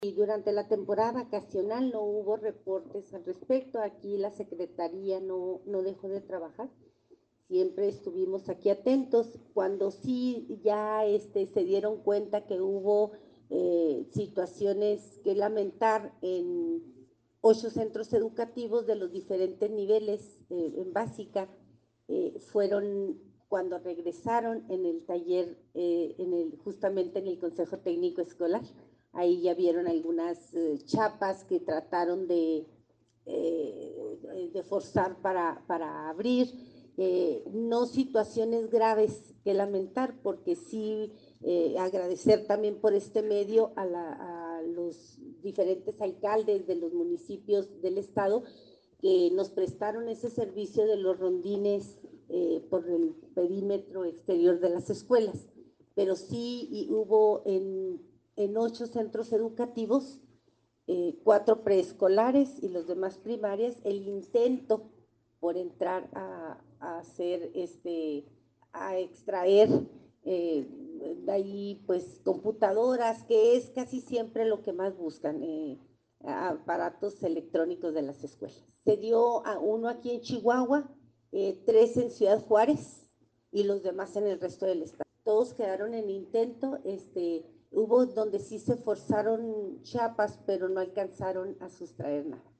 AUDIO: SANDRA GUTÍERREZ, TITULAR DE LA SECRETARÍA DE EDUCACIÓN Y DEPORTE (SEYD)